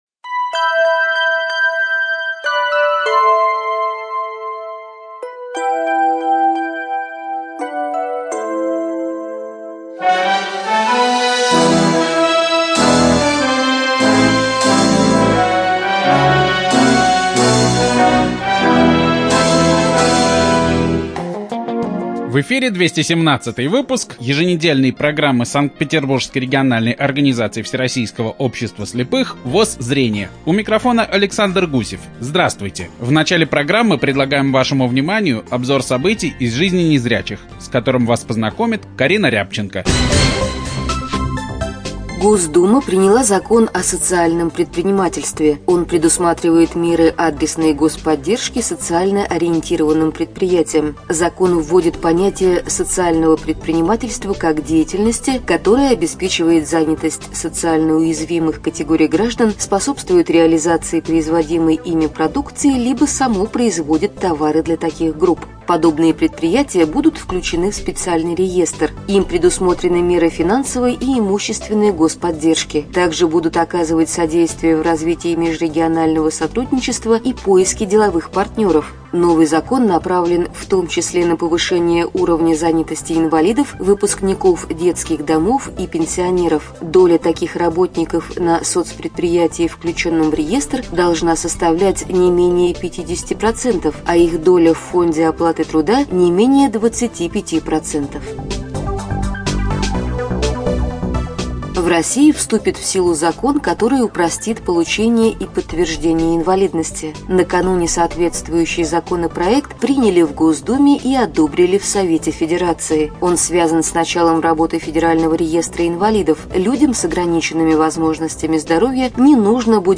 ЖанрРеабилитация, Радиопрограммы
Звучит выступление команды Контакт на реабилитационно-художественном конкурсе «Кто во что горазд», завоевавшей второе место.